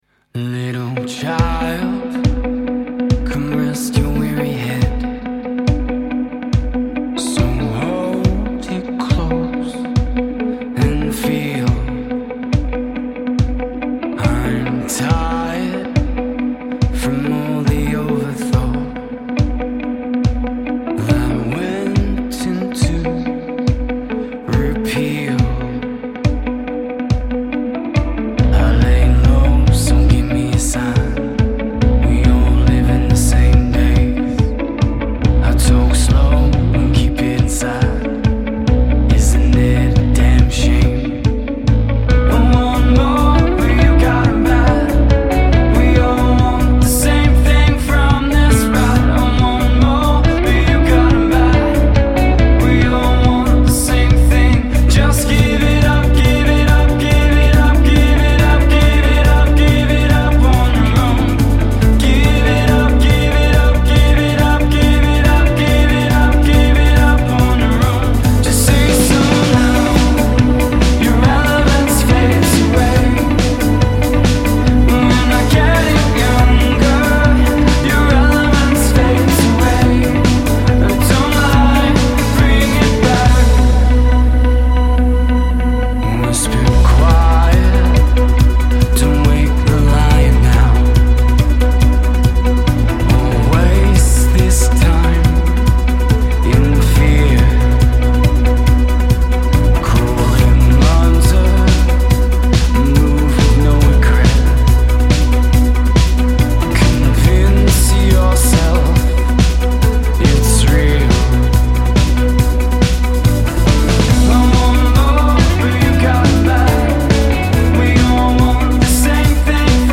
Australian six-piece